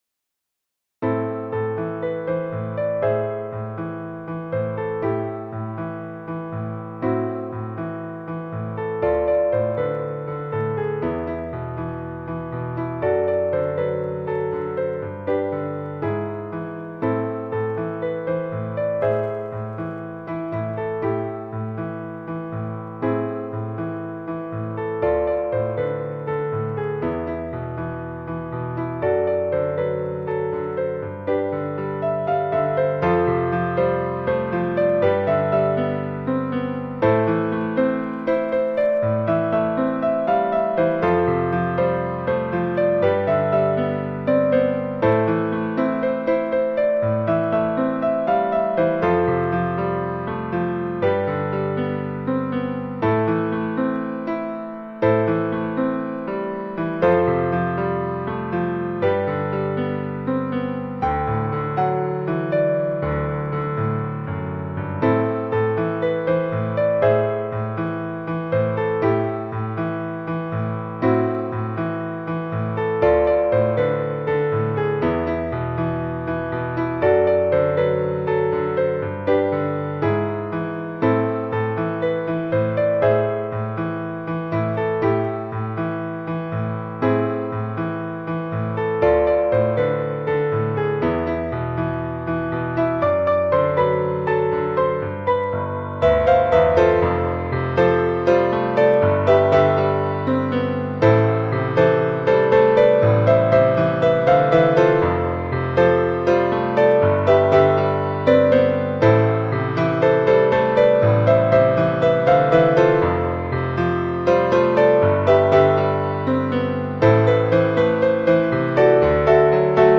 Ноты для фортепиано.